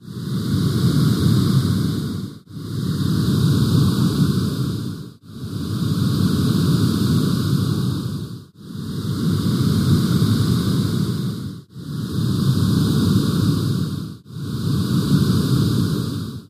Alien creature breathes in long heavy breaths. Loop Monster, Creatures Breathe, Alien Eerie, Breathe